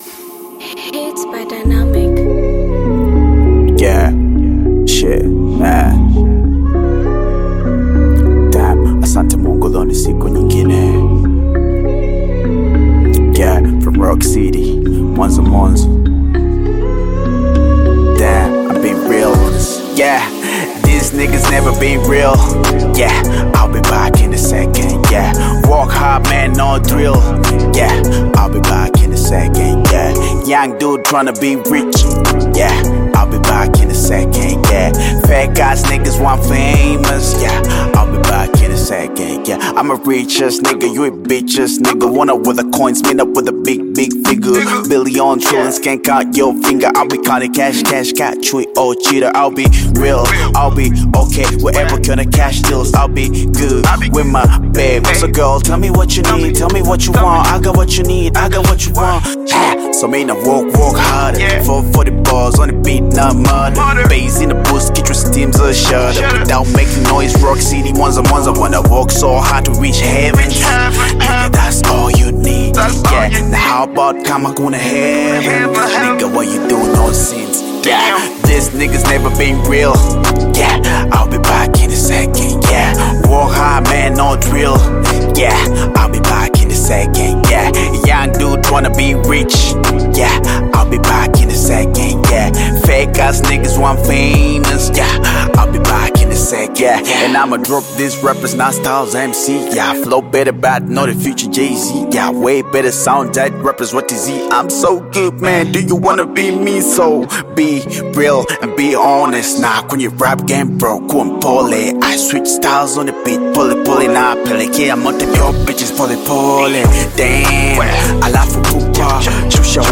Tanzanian Bongo Flava
Bongo Flava song